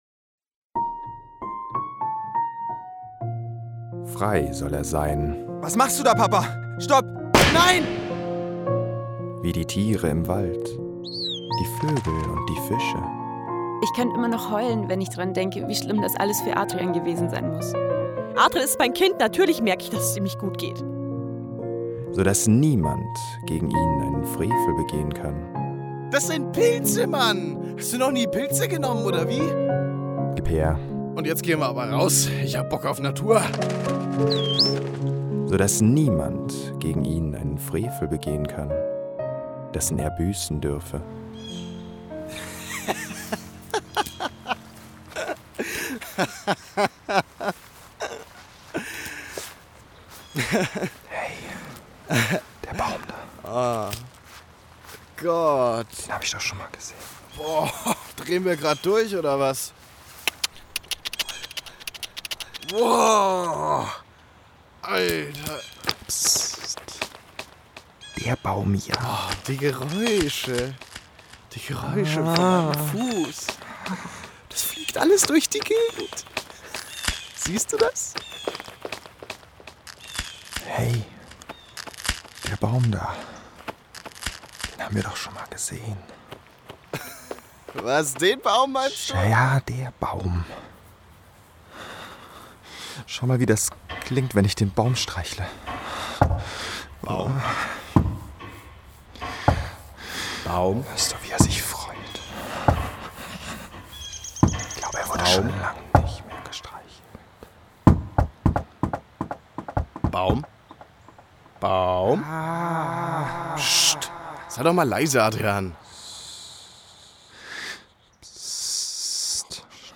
33. Hörspiel
Hoerspiel_Vogelfrei_Teil2.mp3